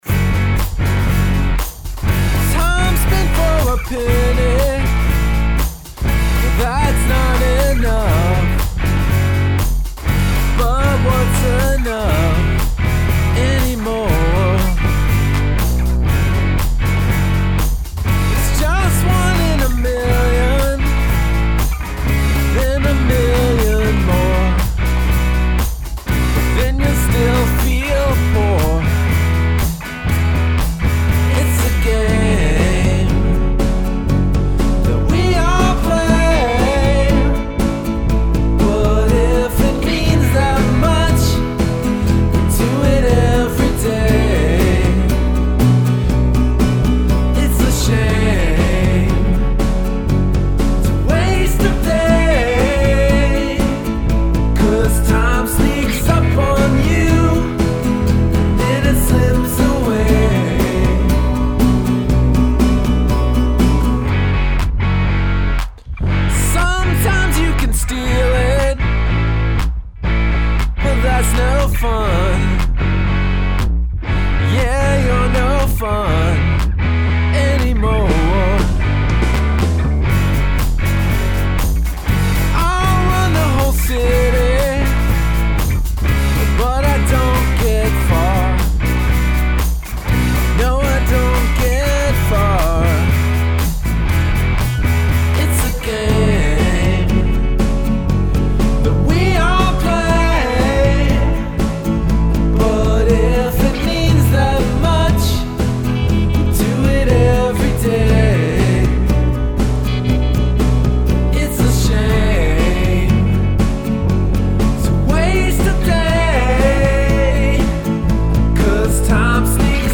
(demos)